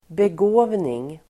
Uttal: [beg'å:vning]